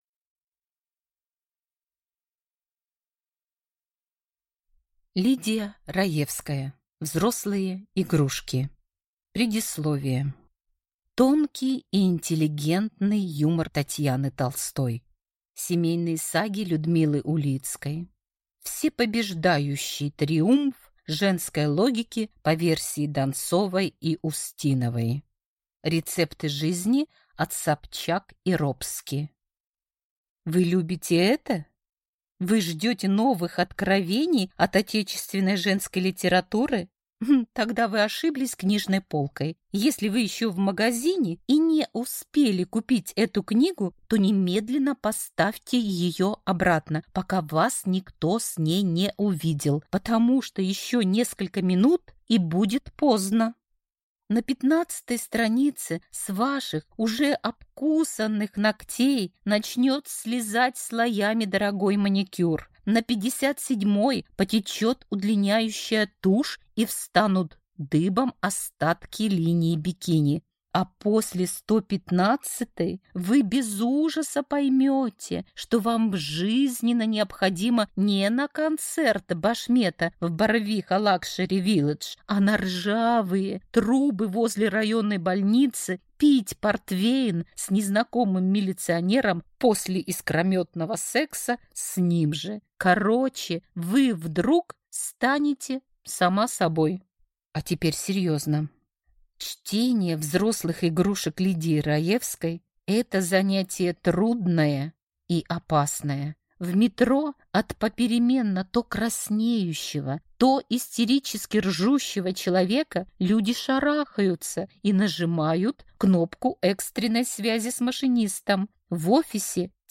Аудиокнига Взрослые игрушки | Библиотека аудиокниг